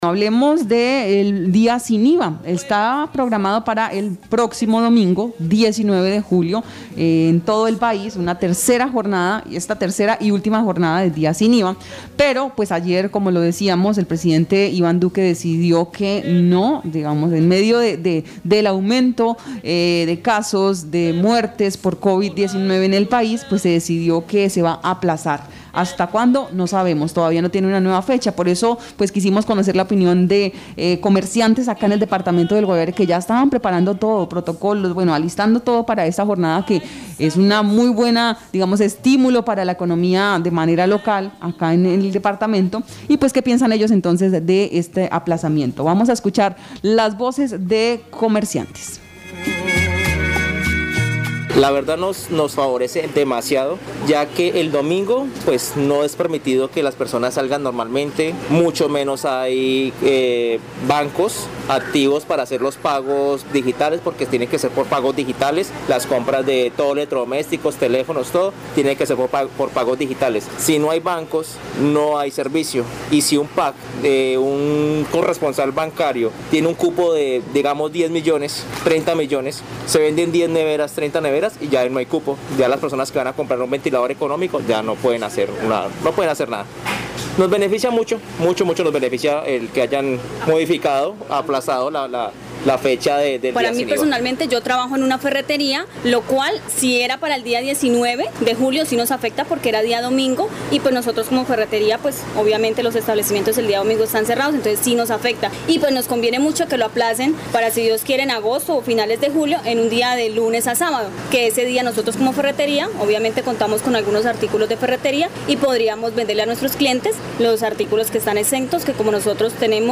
Marandua Noticias realizó un sondeo entre el comercio de San José del Guaviare con el propósito de conocer la opinión de los comerciante con relación a el aplazamiento de la jornada del día sin IVA, ordenada por el Presidente Iván Duque y encontramos que la mayoría está de acuerdo con la decisión del mandatario.